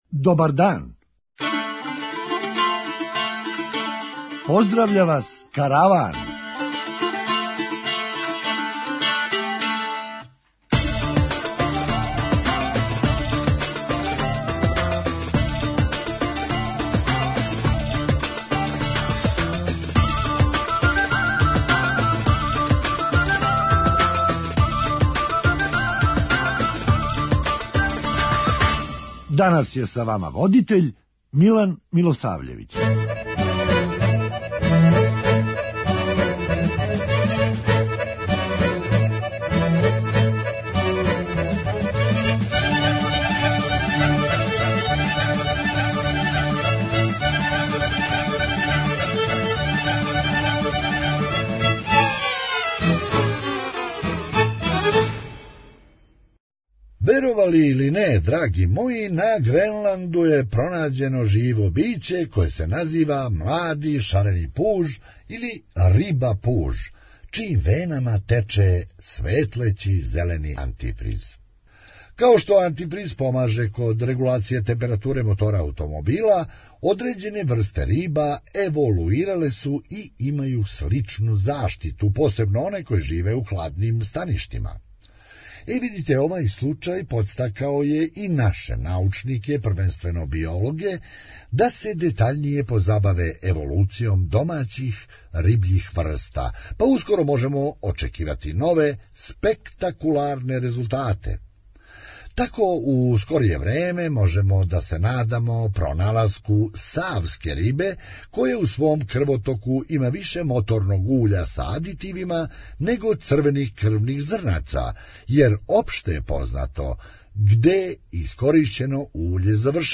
Хумористичка емисија
А уколико водостај још мало опадне појавиће се и олупине разних "тристаћа", "стојадина" и "лада". преузми : 8.95 MB Караван Autor: Забавна редакција Радио Бeограда 1 Караван се креће ка својој дестинацији већ више од 50 година, увек добро натоварен актуелним хумором и изворним народним песмама.